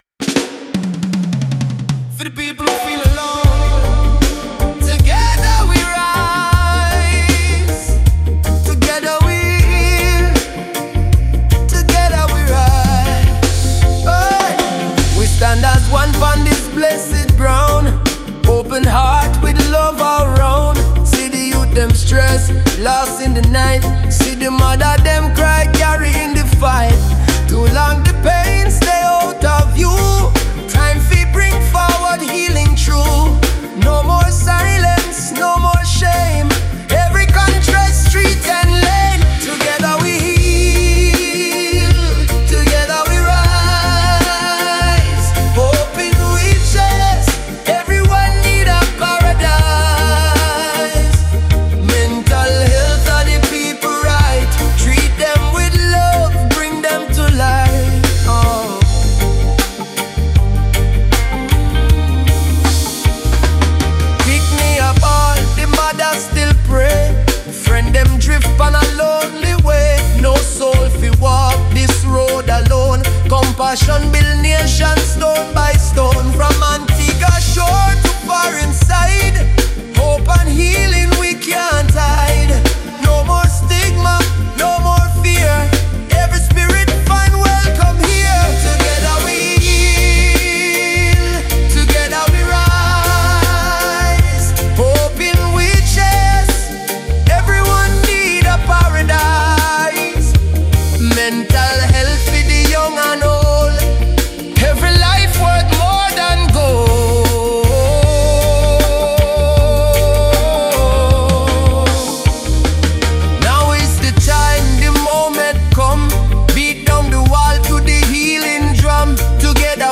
A gentler mid-album track.
Female backing vocals
give it a soulful, gospel-reggae feel.